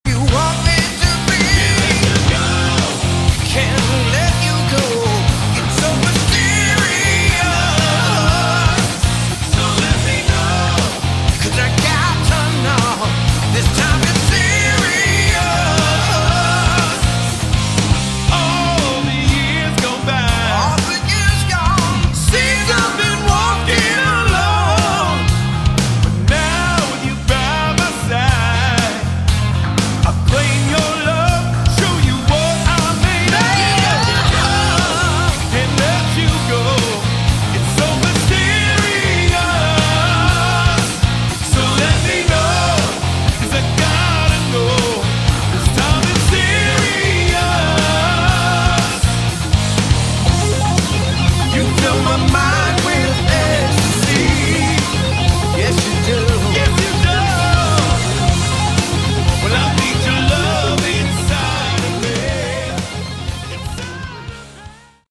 Category: Hard Rock
Vocals, Keys
Drums
Bass
Guitars